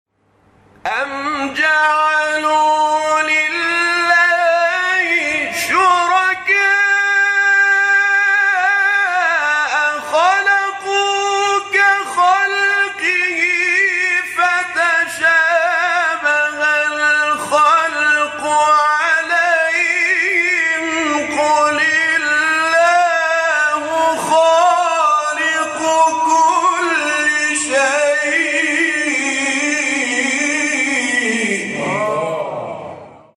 شبکه اجتماعی: فرازهای صوتی از تلاوت قاریان برجسته و ممتاز کشور را که به‌تازگی در شبکه‌های اجتماعی منتشر شده است، می‌شنوید.